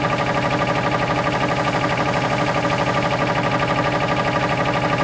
sounds_compressor_motor_01.ogg